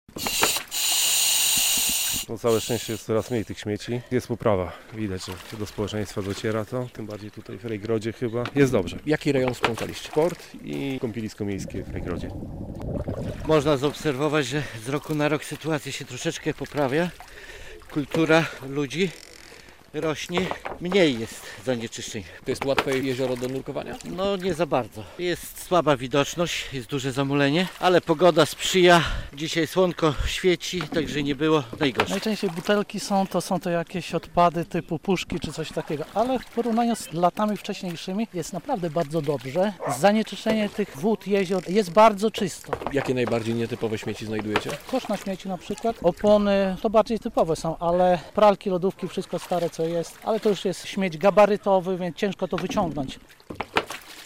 Jak mówili nurkowie śmieci jest coraz mniej.